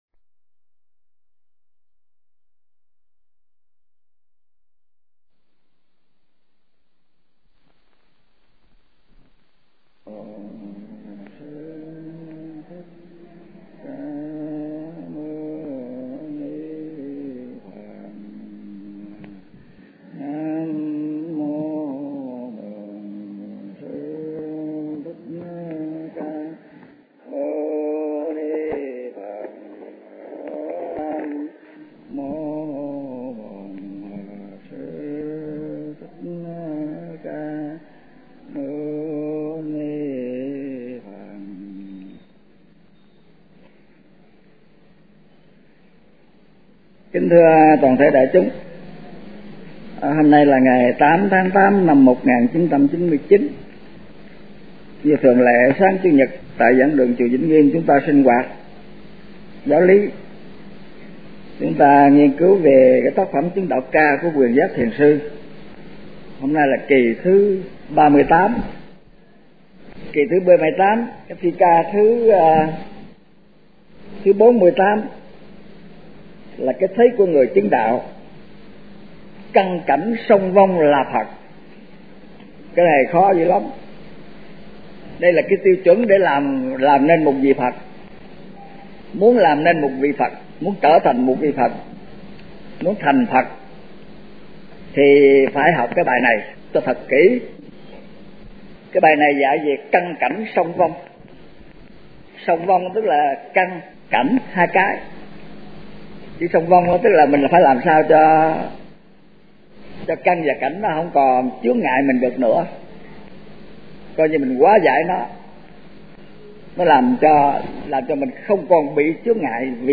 Kinh Giảng Chứng Đạo Ca